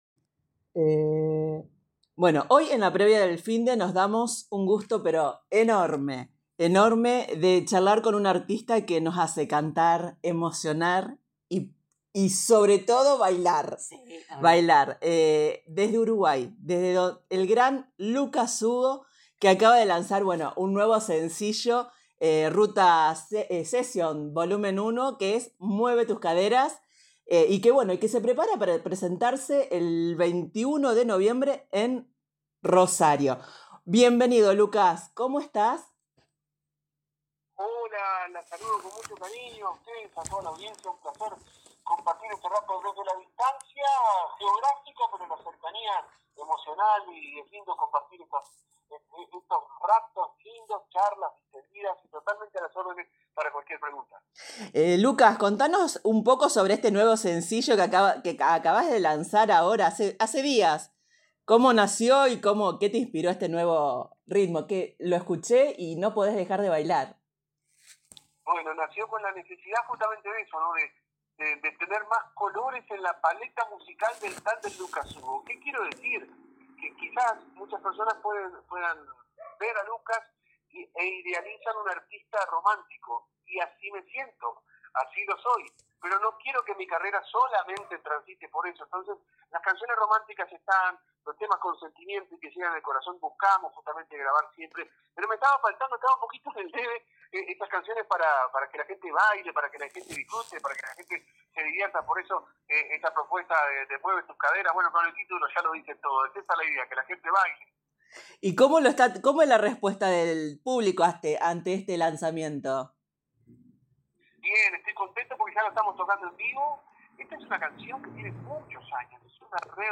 En la antesala de un nuevo fin de semana, La Previa del Finde se dio un gusto enorme: charlar con uno de los artistas más queridos y carismáticos del Río de la Plata, Lucas Sugo, quien acaba de lanzar su nuevo sencillo “Mueve tus caderas”, parte del proyecto “Ruta Sesión Volumen 1”.